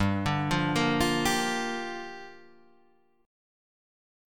Gm7 chord